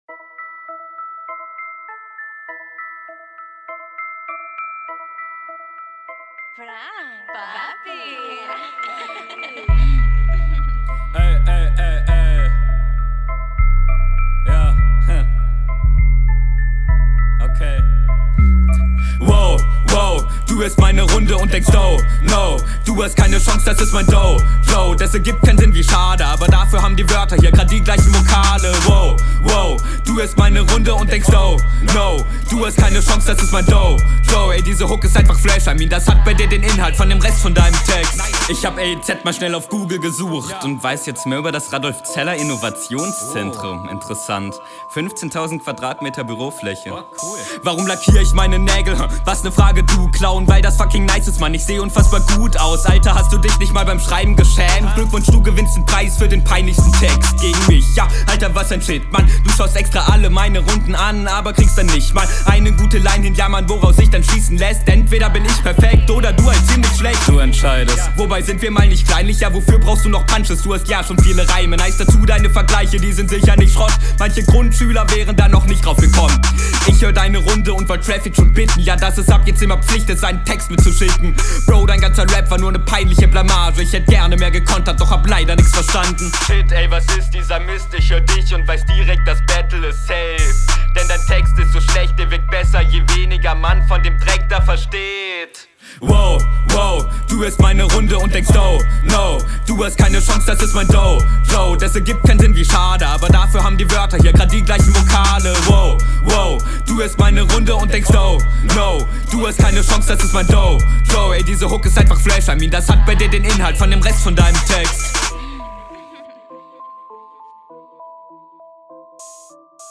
kommst direkt viel besser auf den Beat. Delivery super.